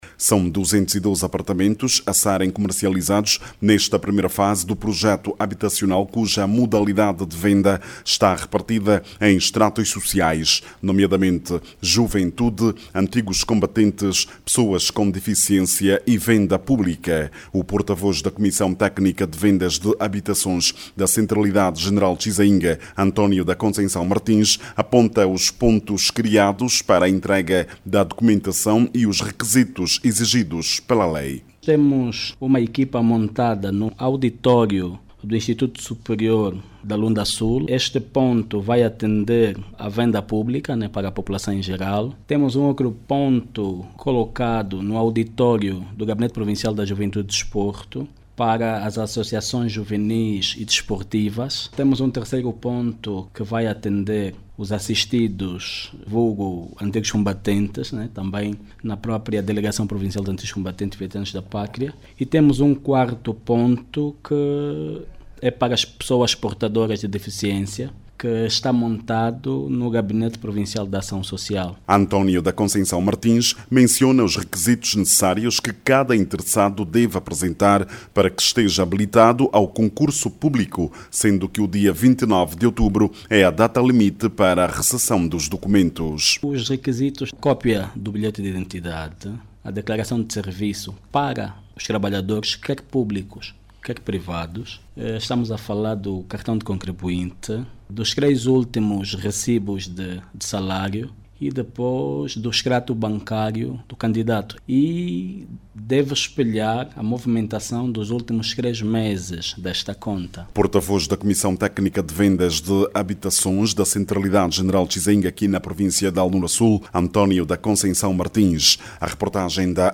A modalidade de comercialização dos mais de duzentos apartamentos, nesta primeira fase está repartida por estratos sociais. Saiba mais dados no áudio abaixo com o repórter